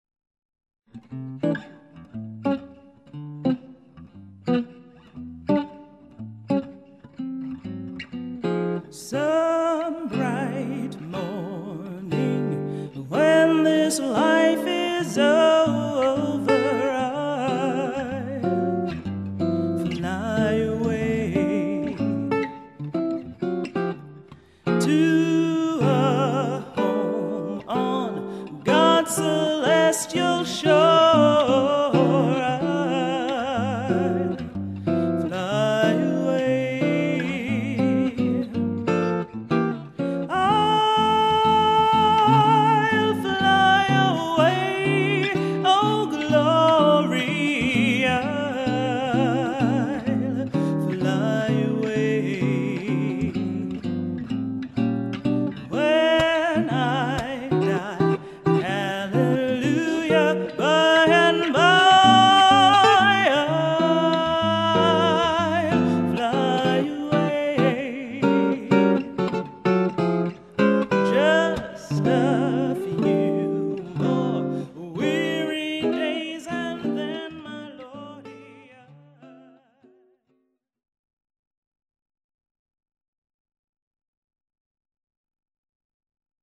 Vocals
Piano
Bass
Drums/Perc.
Guitar